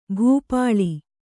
♪ bhū pāḷi